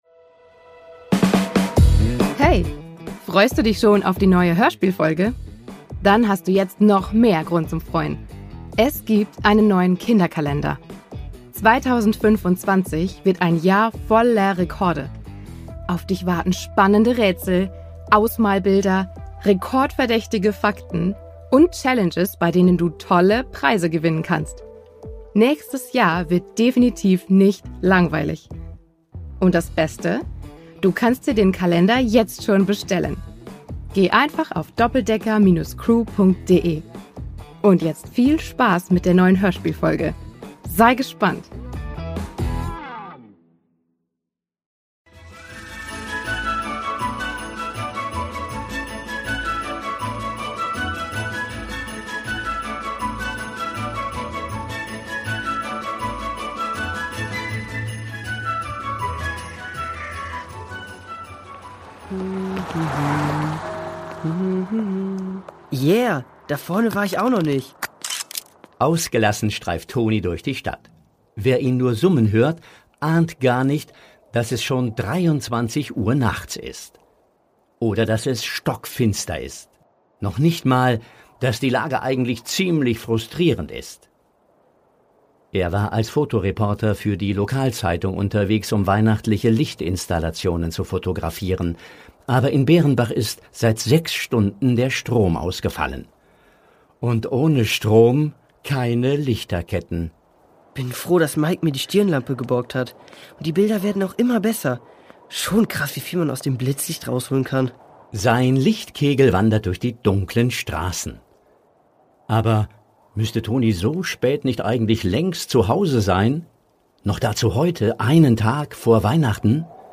Weihnachten 3: Eine mysteriöse Begegnung | Die Doppeldecker Crew | Hörspiel für Kinder (Hörbuch) ~ Die Doppeldecker Crew | Hörspiel für Kinder (Hörbuch) Podcast